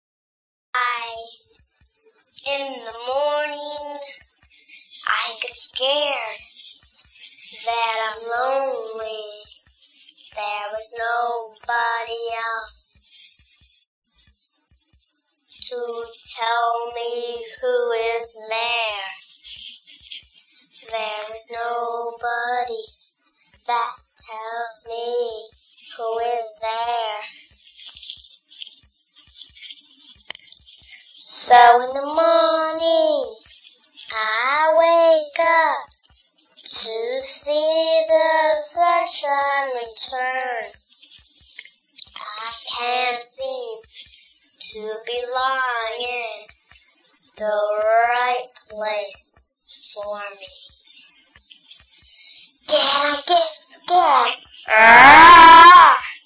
RX7 voice only trainer